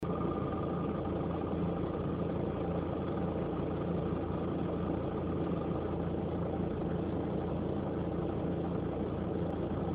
312D_Leerlauf
312D_Leerlauf.mp3